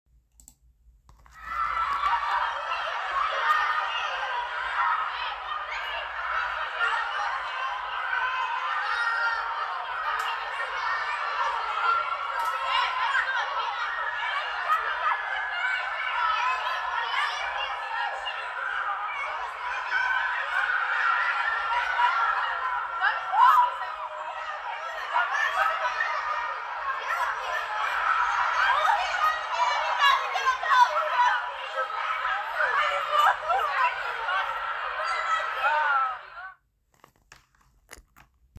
school-playground-sound-effect.mp3